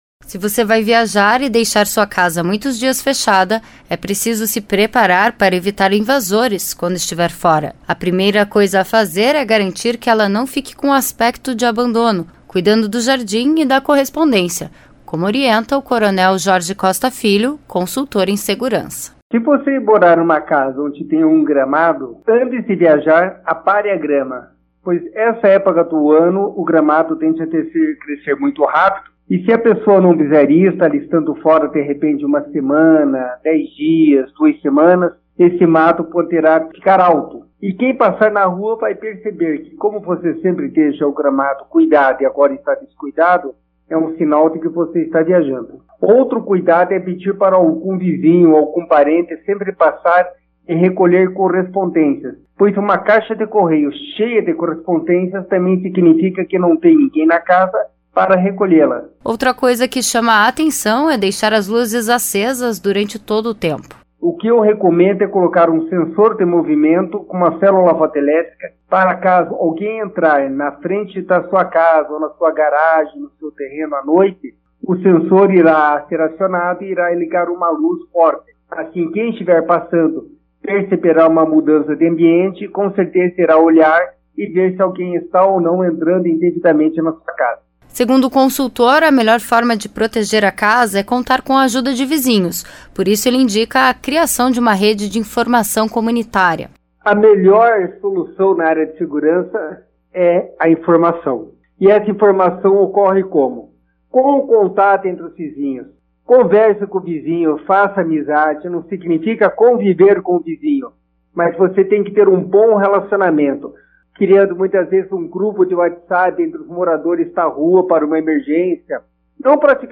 Para não ter uma surpresa desagradável ao voltar de viagem é preciso preparar a casa contra possíveis invasores. Acompanhe as dicas de segurança de um consultor.